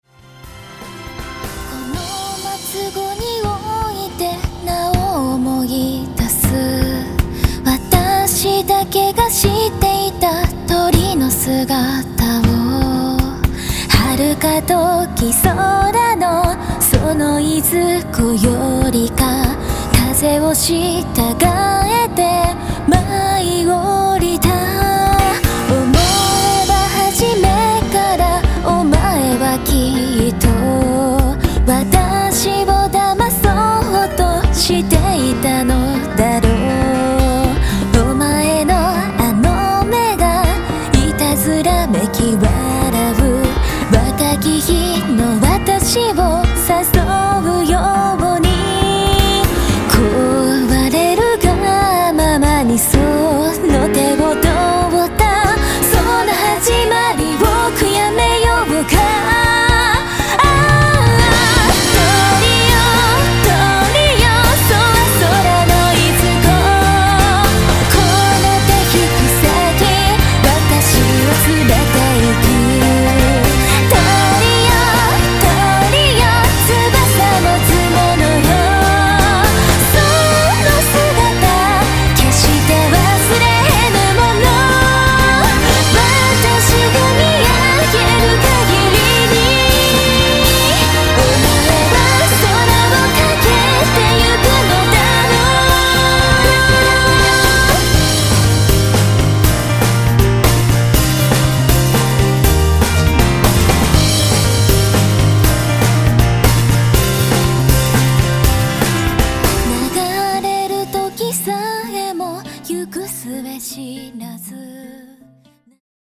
東方マルチジャンルアレンジアルバムです。
歌物を中心に、ロック、ポップス、和楽器モチーフを多めに、全体的にやや重くおどろおどろしく！！